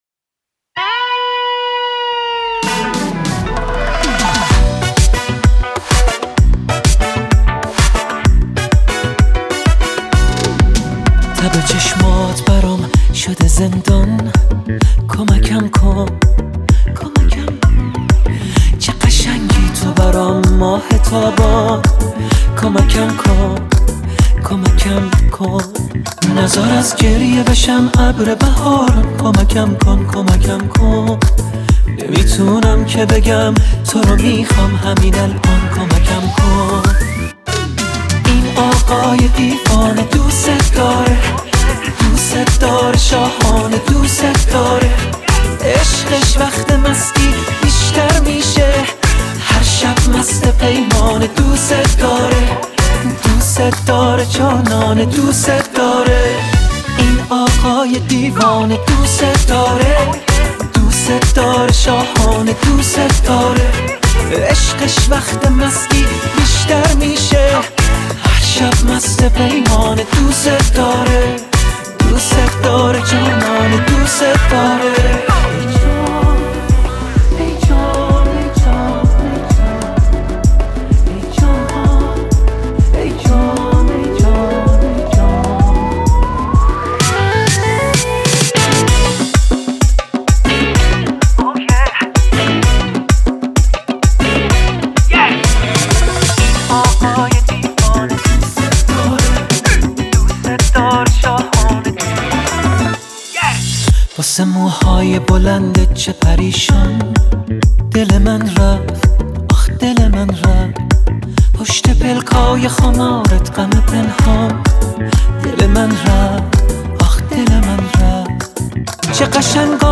اهنگ جدید و شاد و خوب